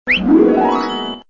Computer Sounds